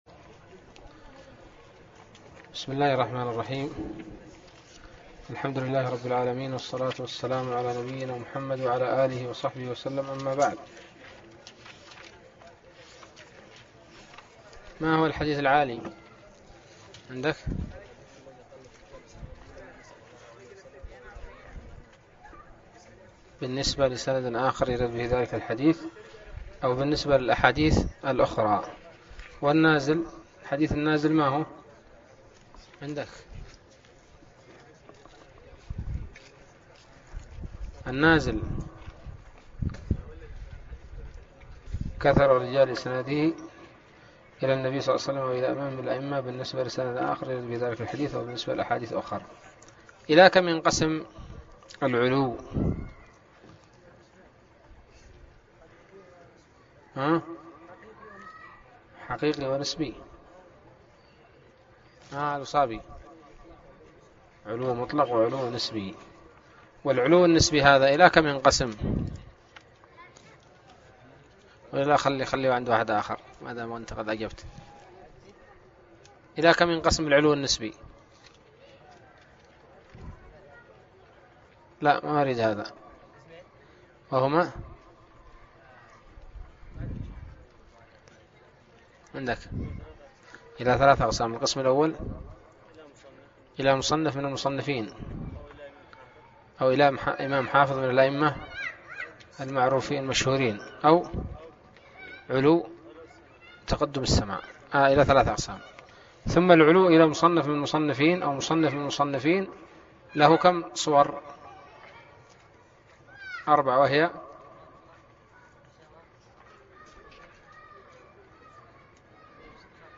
الدرس الثامن والأربعون من الباعث الحثيث